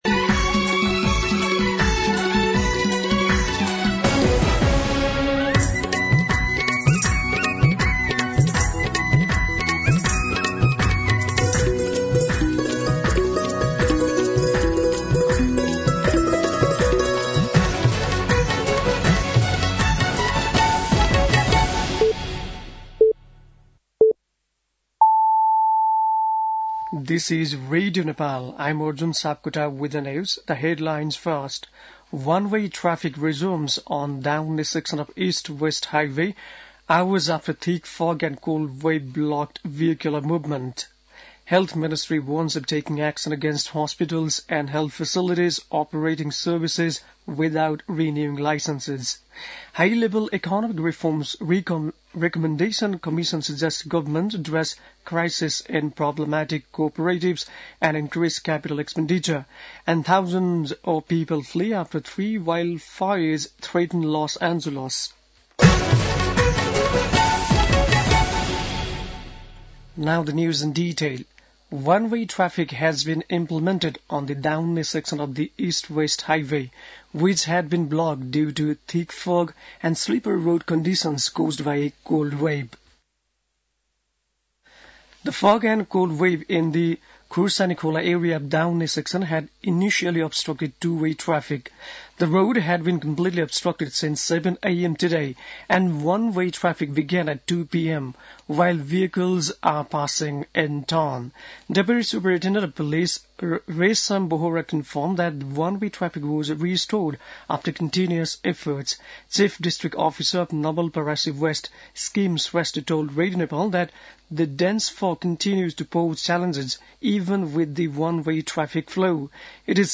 बेलुकी ८ बजेको अङ्ग्रेजी समाचार : २५ पुष , २०८१
8-PM-English-News-9-24.mp3